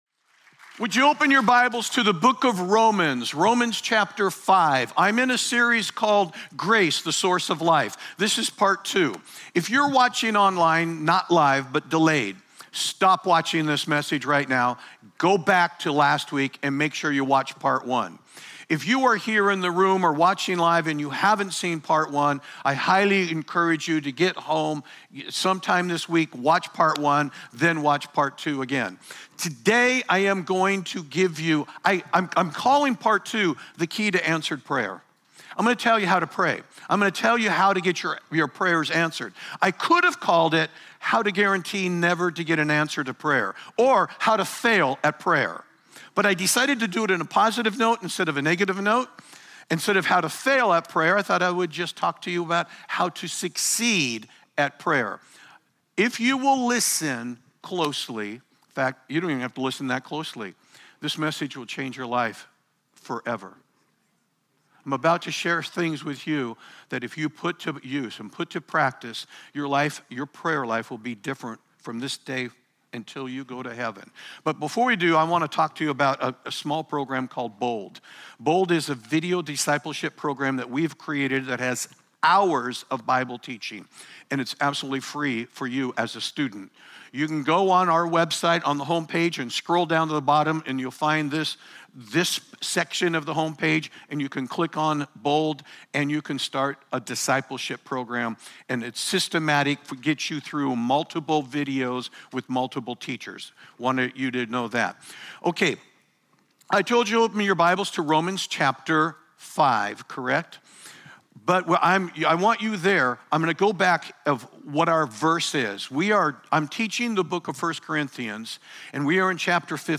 Sermons | Church of Grace